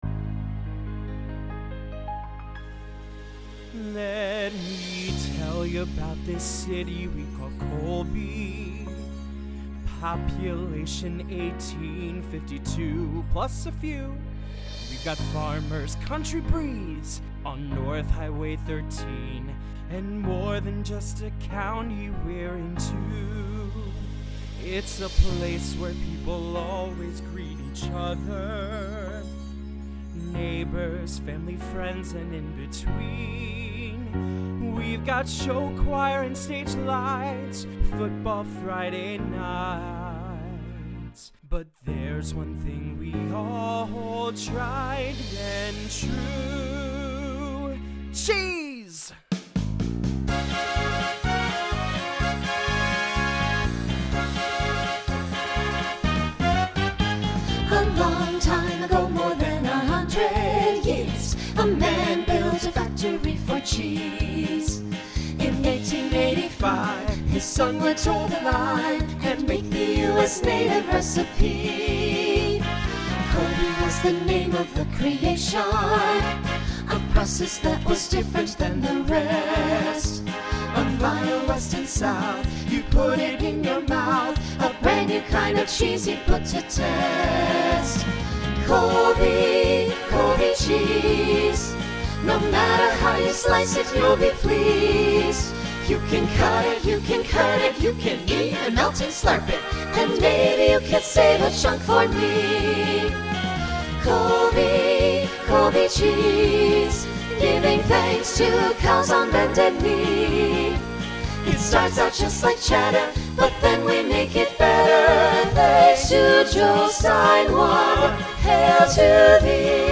Country , Folk
Original Song Show Function Novelty Voicing SATB